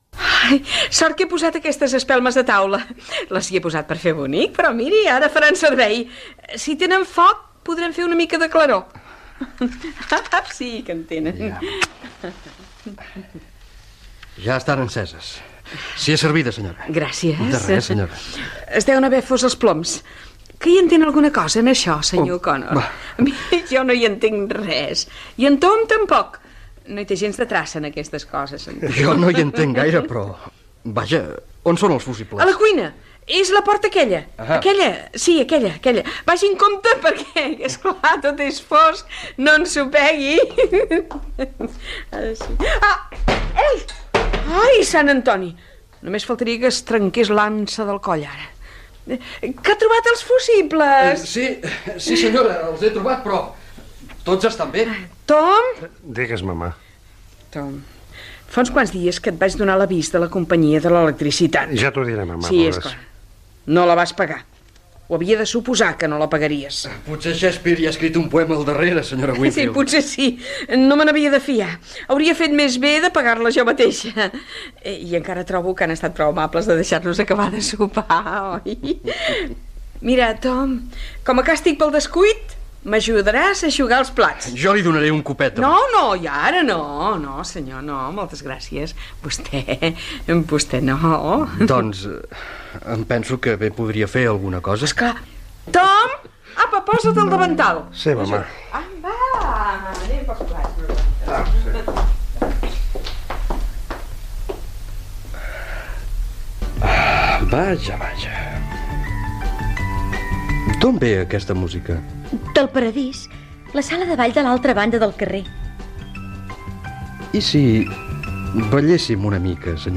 Fragment de l'adaptació radiofònica de l'obra "Figuretes de vide" de Tennessee Williams
Ficció